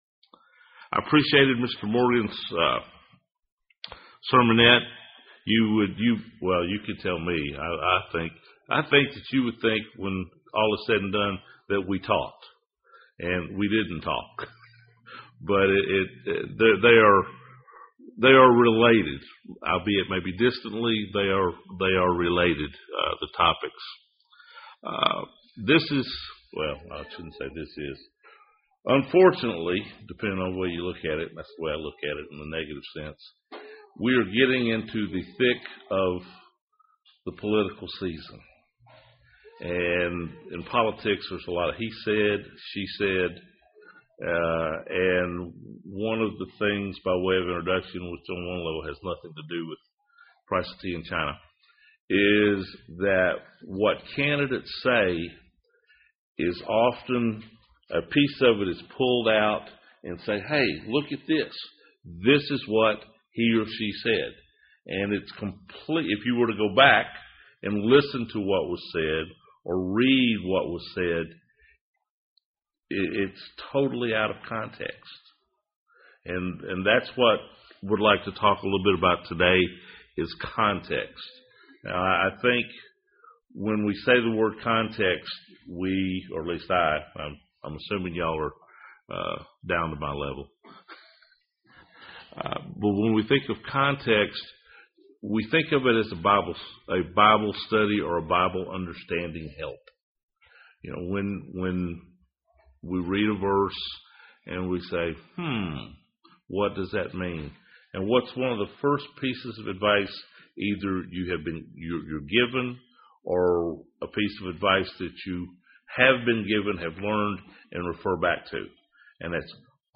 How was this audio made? Given in Huntsville, AL Murfreesboro, TN